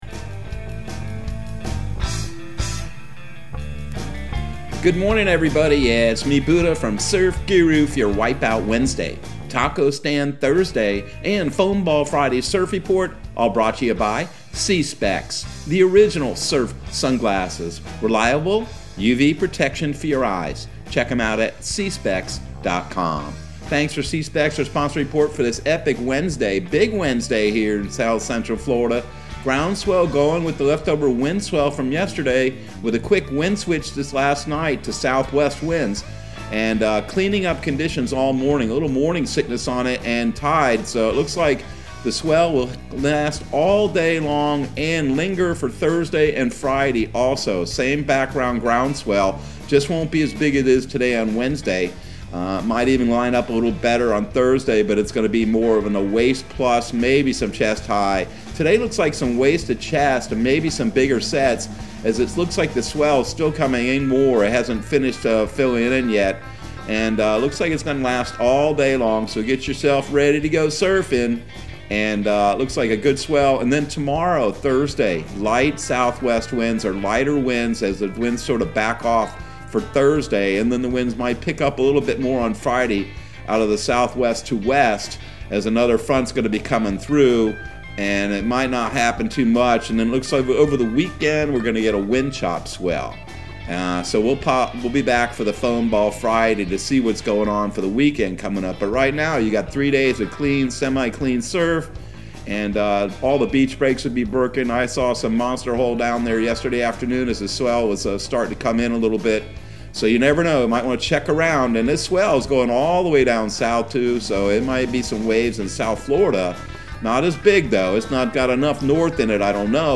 Surf Guru Surf Report and Forecast 01/05/2022 Audio surf report and surf forecast on January 05 for Central Florida and the Southeast.